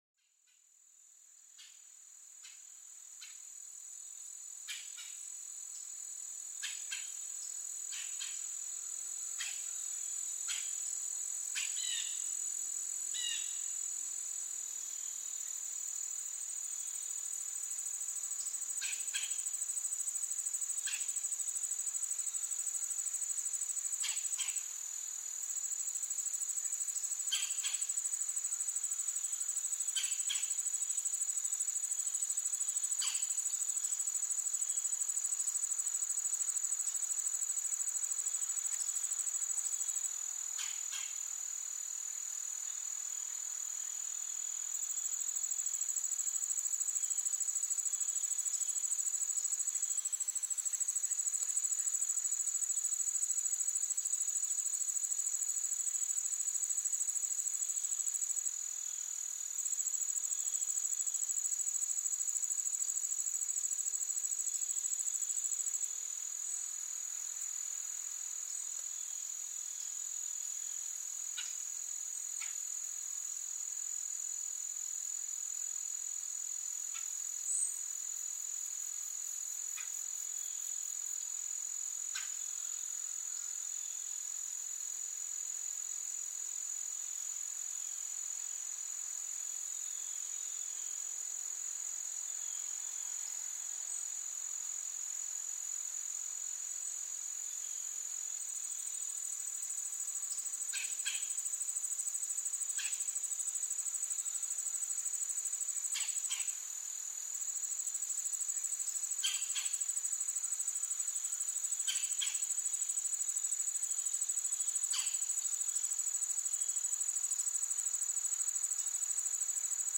Escucha los sonidos calmantes del bosque, desde el suave gorjeo de los pájaros hasta el susurro de las hojas en la brisa. Descubre cómo esta sinfonía natural promueve la relajación, reduce el estrés y mejora la concentración.